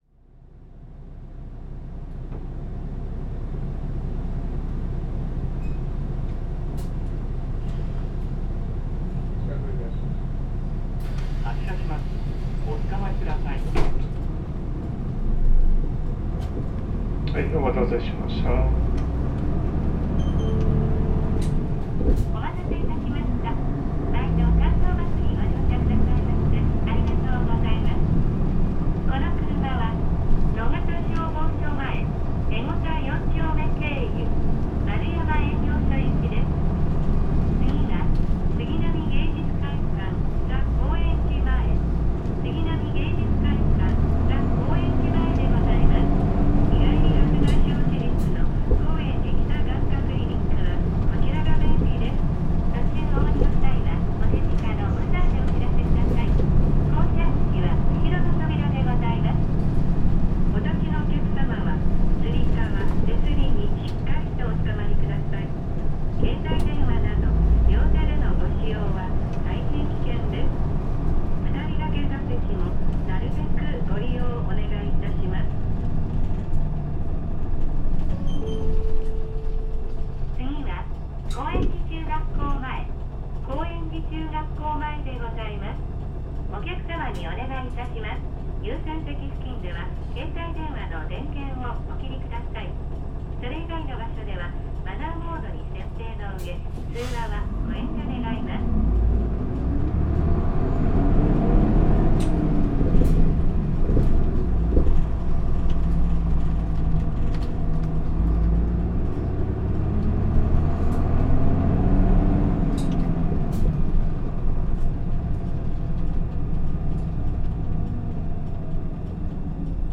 関東バス 日産ディーゼル KL-UA452KAN改 ・ 走行音(全区間) (28.4MB*) 収録区間：中35系統 五日市街道営業所→中野駅 関東バスの主力車として活躍するKL-UA。
排ガス規制をクリアするためKC代とはエンジンが異なっており、ガラガラ音がやや強めだが、UAらしい重そうな響きも かなり残っている。収録の路線は大型バスにしては狭い道やカーブ、途中の信号停車が多めだが、そんな中でも定時運転を目指し懸命に走っていた。後扉の「ピピピ ピピピ ピピピ」と鳴るドアチャイムが独特だが、これは関東バス独自のもの。